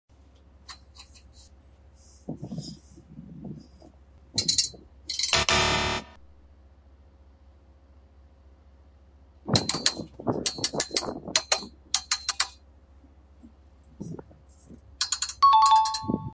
Welding steel tubes the right sound effects free download